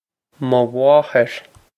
mo mháthair muh wah-hir
muh wah-hir
This is an approximate phonetic pronunciation of the phrase.